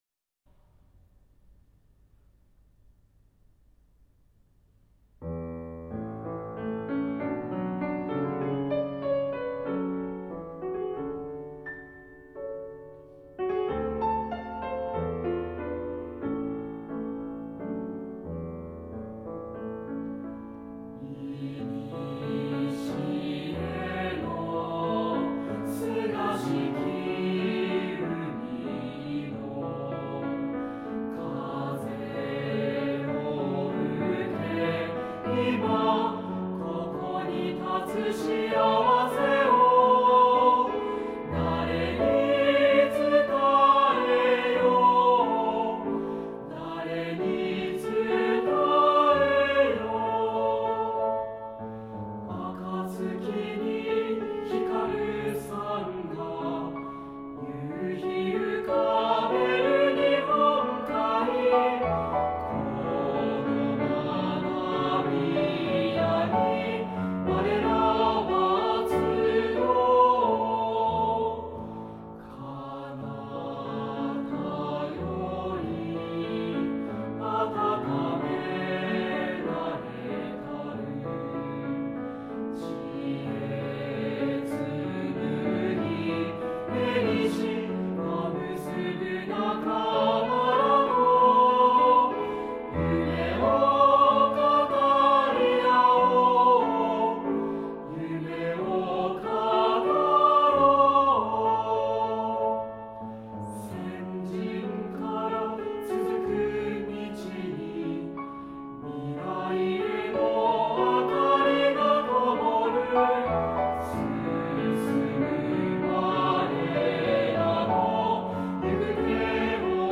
aishouka_ongen_piano.mp3